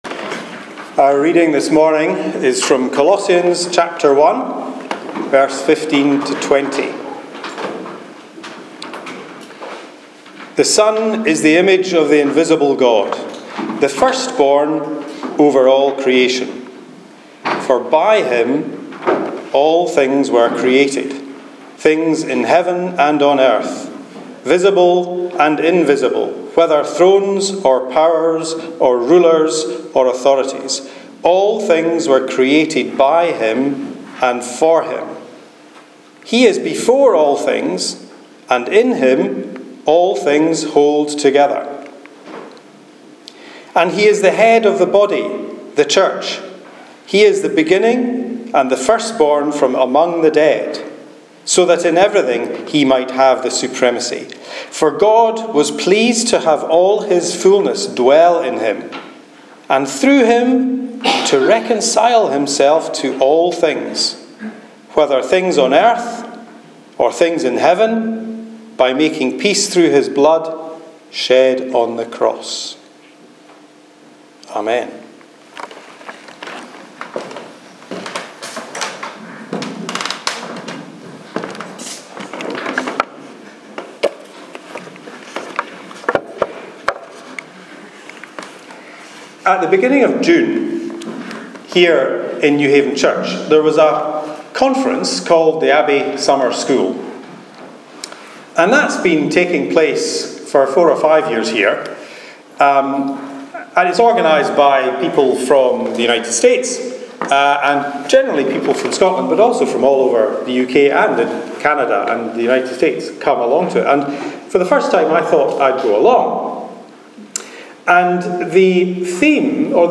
Message on Creation and Community- 16th July 2017